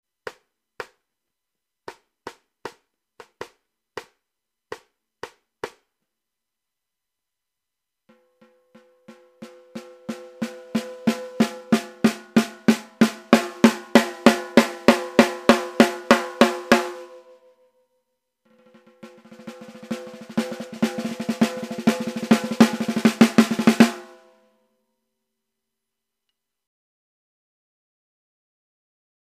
Auf Bitten in meinem Marschtrommelkabinett habe ich heute mal meine 5 Snaredrums aufgenommen.